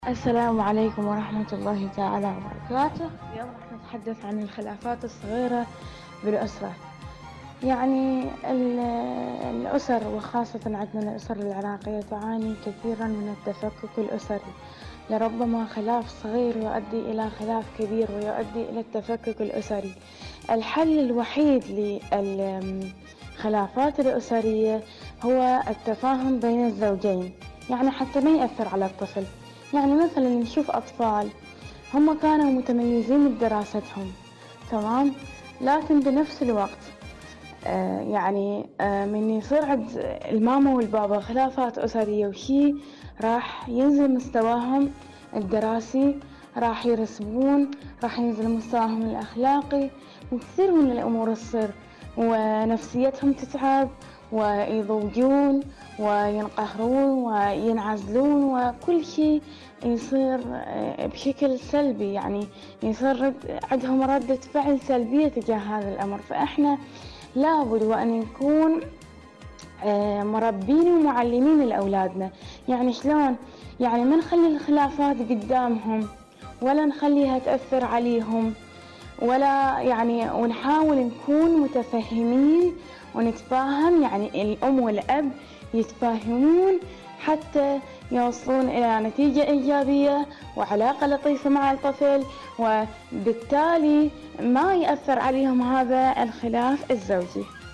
مشاركة صوتية
إذاعة طهران- معكم على الهواء